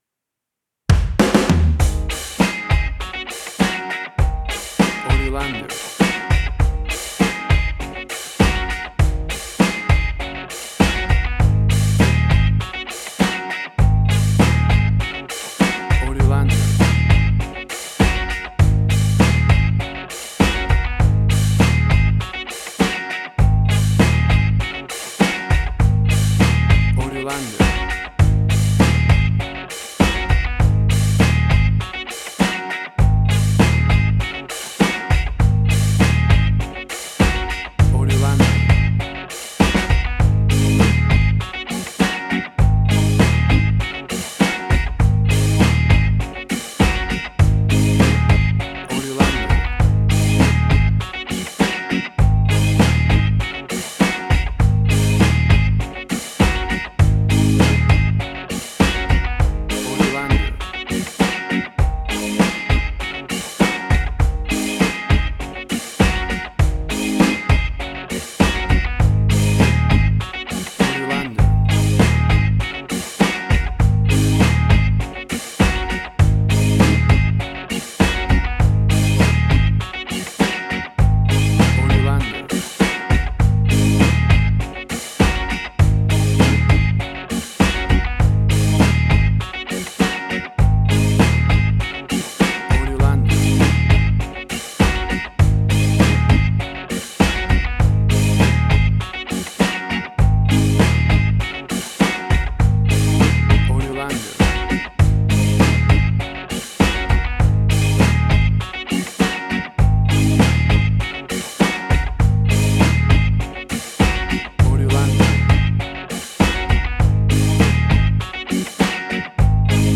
Classic reggae music with that skank bounce reggae feeling.
Tempo (BPM): 100